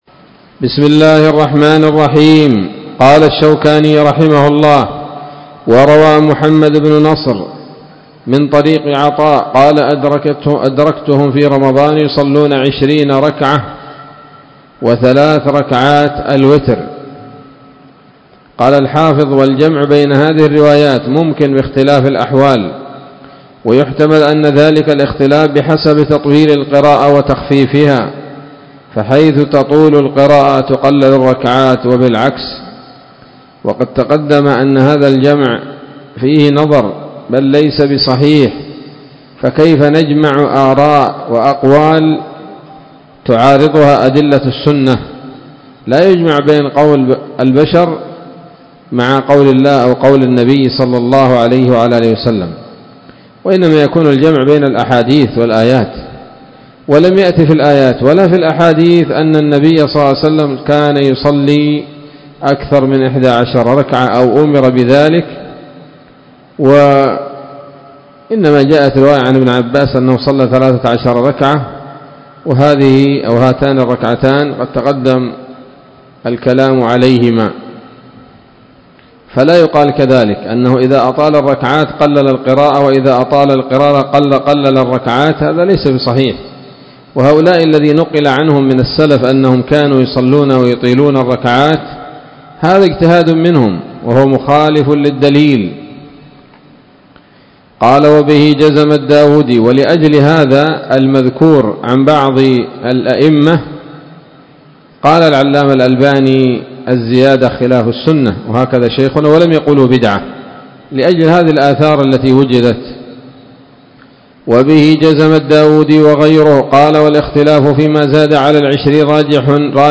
الدرس الرابع والعشرون من ‌‌‌‌أَبْوَابُ صَلَاةِ التَّطَوُّعِ من نيل الأوطار